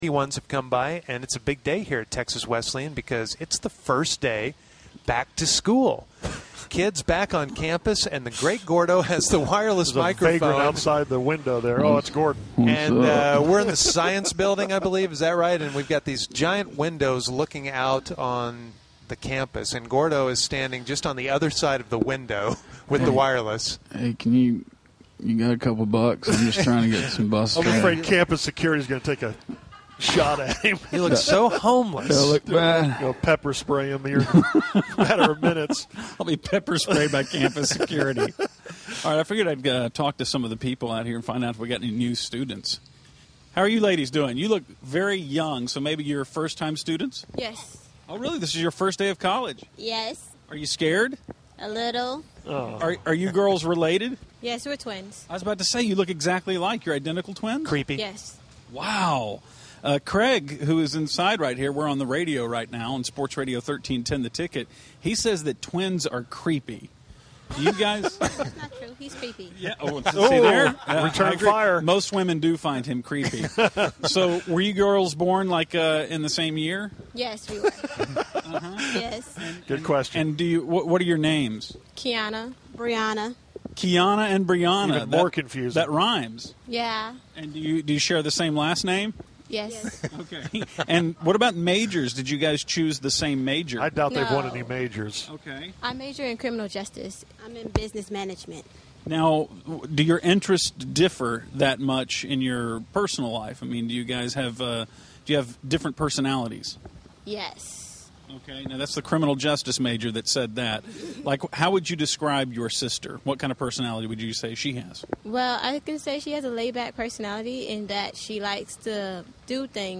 wireless at Texas Wesleyan University.